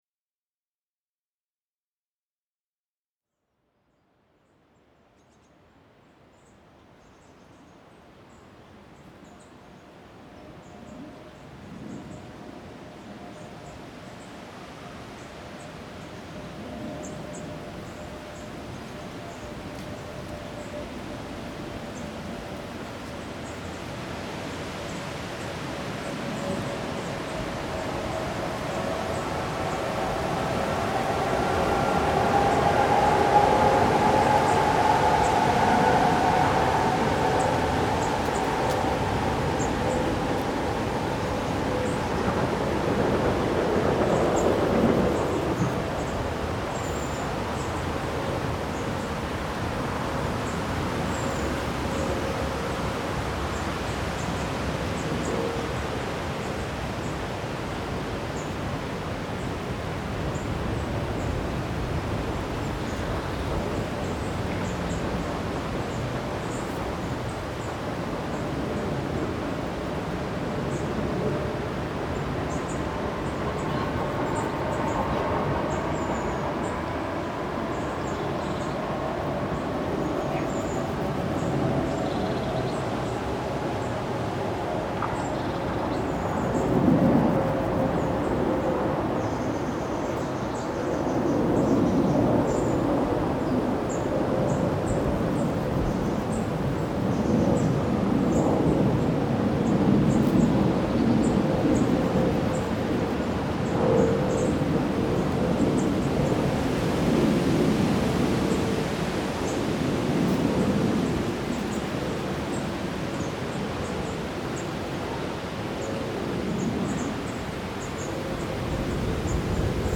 Tower Hamlets Cemtery Park
8 locations in london, 4 urban spaces, 4 unspoilt nature…
8LondonLocations_07_TowerHamletsCemeteryPark.mp3